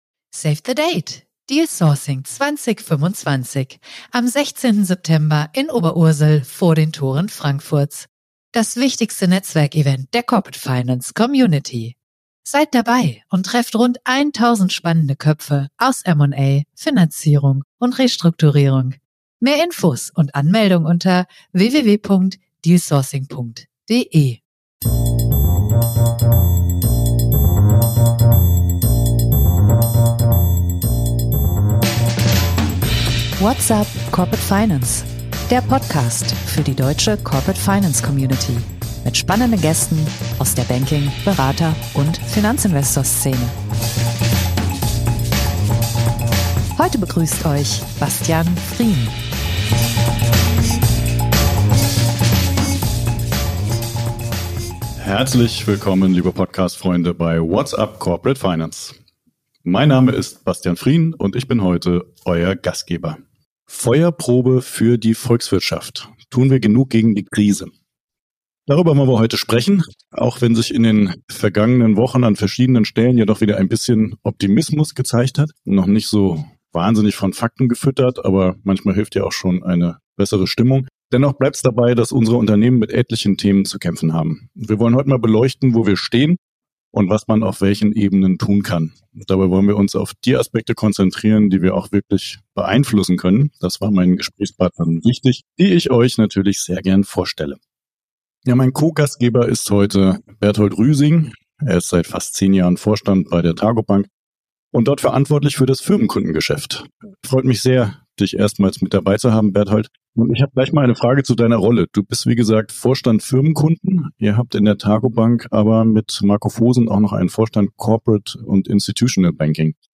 Darüber diskutieren wir mit einem Firmenkundenvorstand, einem Warenkreditversicherer und einem Restrukturierungsberater.